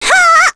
Ophelia-Vox_Casting1.wav